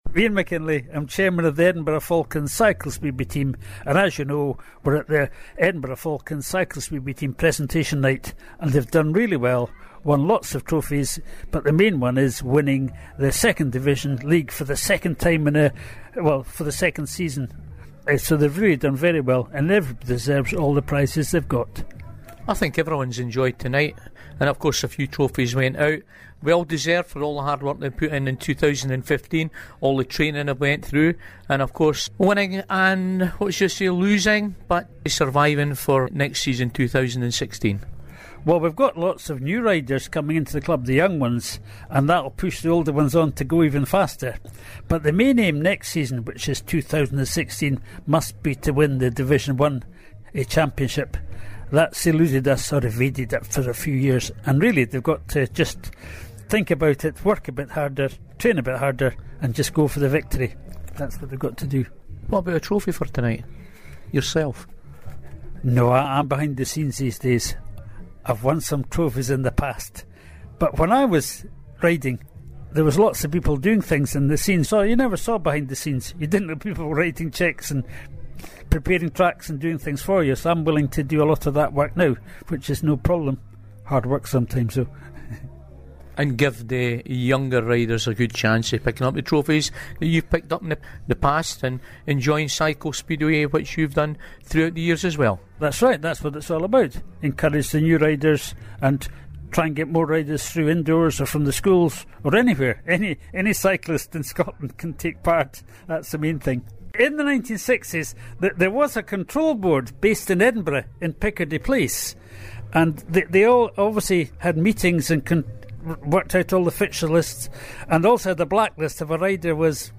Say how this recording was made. Edinburgh Falcons Cycle Speedway Club Presentation Evening 2015